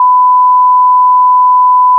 Beeping Noise Sound Button: Unblocked Meme Soundboard
Play the iconic Beeping Noise sound button for your meme soundboard!